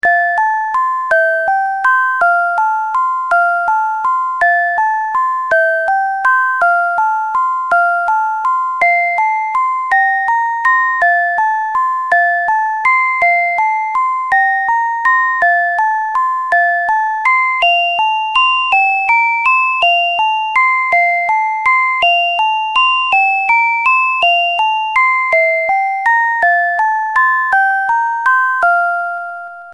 • Dual channel melody with the timber of music box